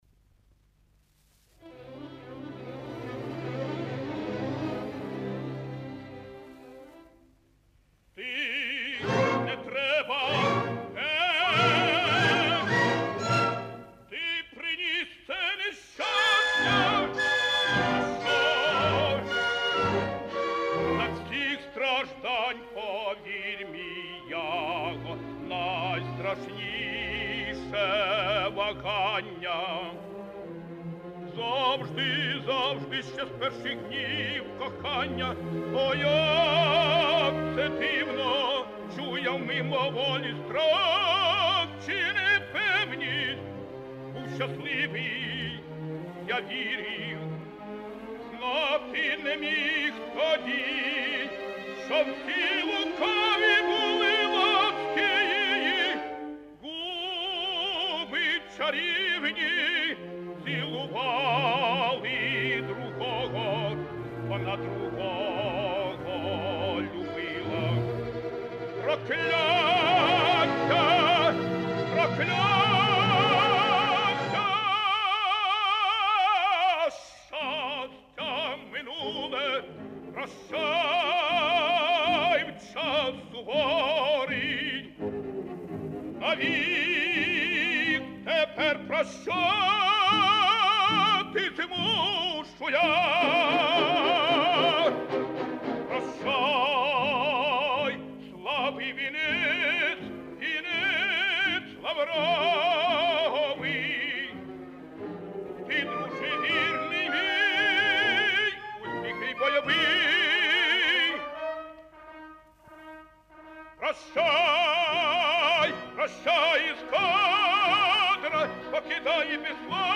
Опера «Отелло». Ария Отелло. Оркестр Киевского государственного театра оперы и балета.